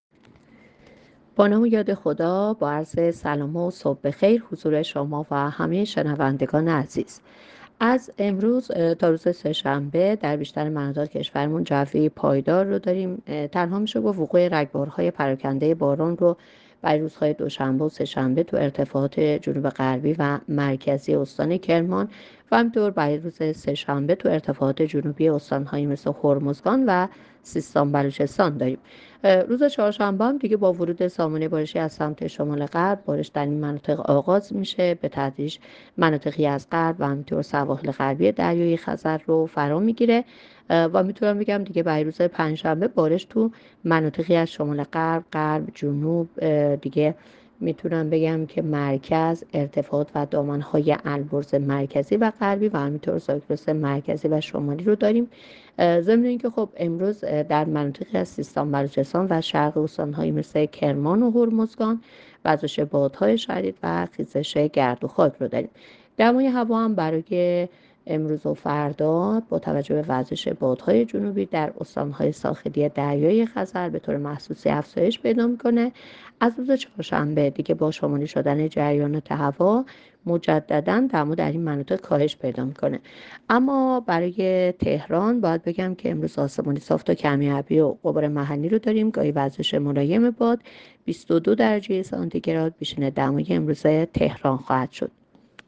گزارش رادیو اینترنتی پایگاه‌ خبری از آخرین وضعیت آب‌وهوای ۲۶ اسفند؛